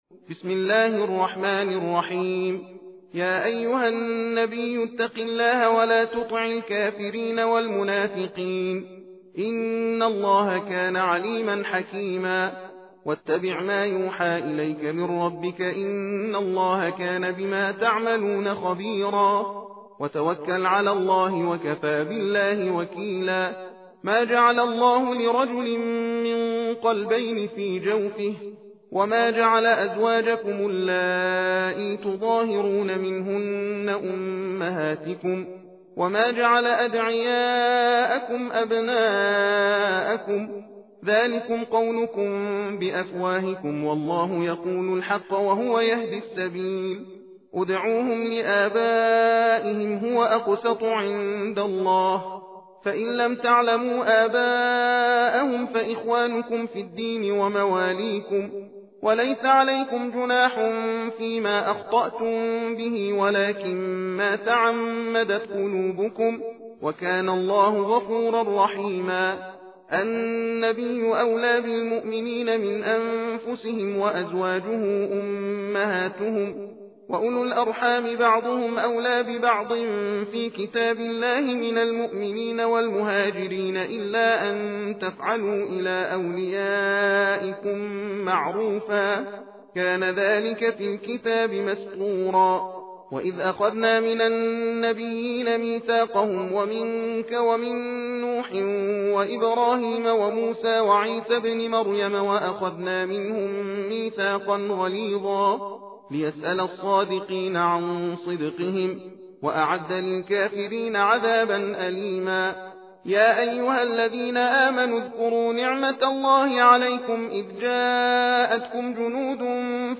تحدیر (تندخوانی) سوره احزاب
تحدیر روشی از تلاوت قرآن است که قاری در آن علی رغم رعایت کردن قواعد تجوید، از سرعت در خواندن نیز بهره می برد، از این رو در زمان یکسان نسبت به ترتیل و تحقیق تعداد آیات بیشتری تلاوت می شود.به دلیل سرعت بالا در تلاوت از این روش برای مجالس ختم قرآن کریم نیز می توان بهره برد.